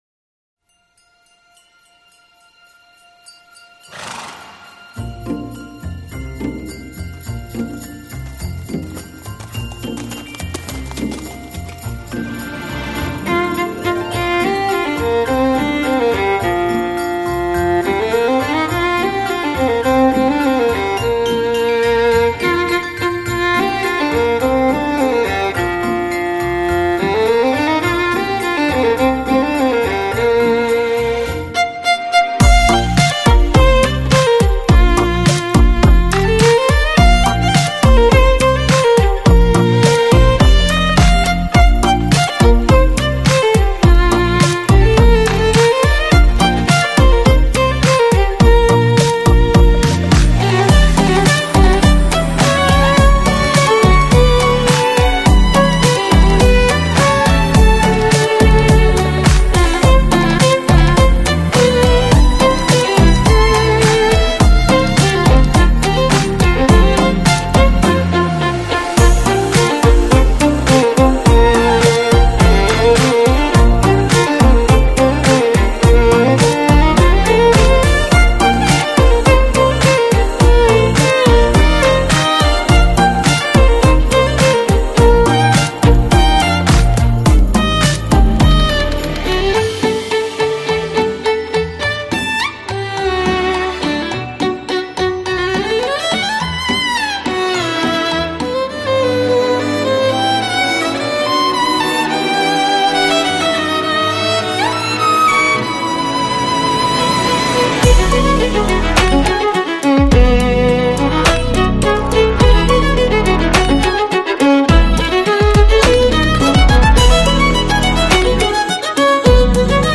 Classical Crossover, Christmas Music Год издания